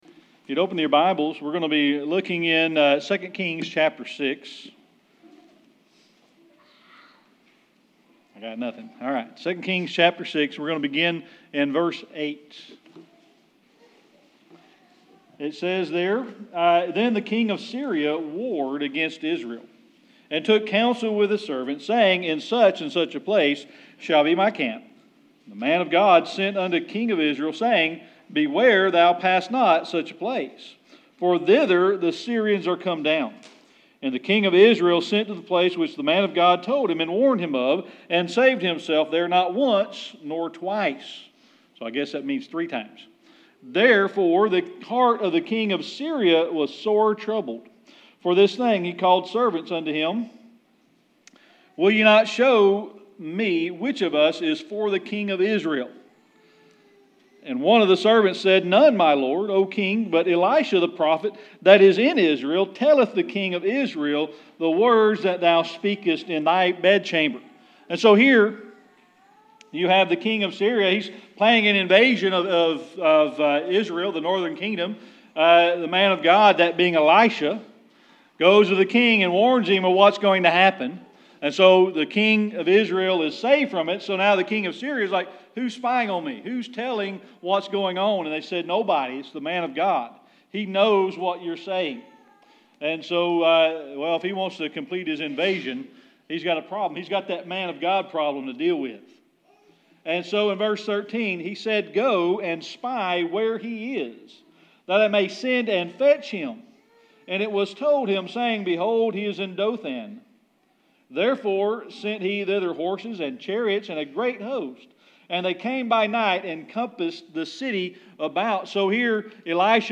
Series: Sermon Archives
Service Type: Sunday Evening Worship